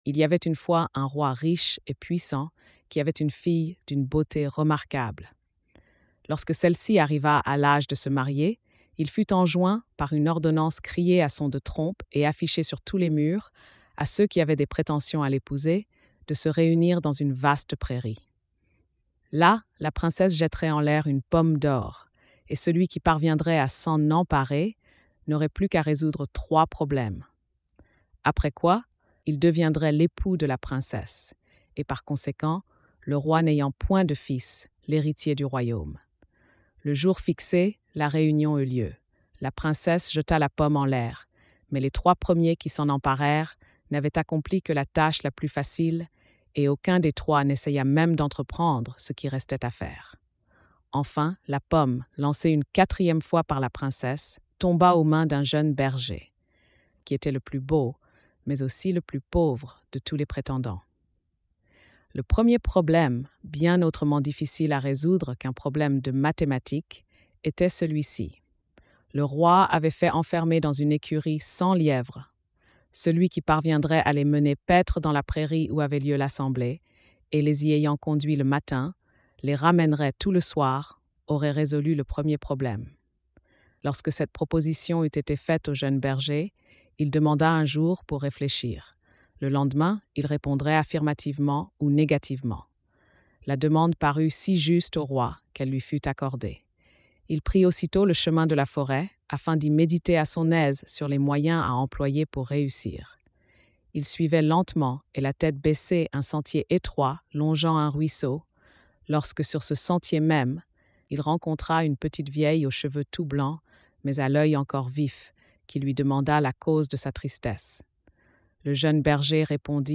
Lecture audio générée par IA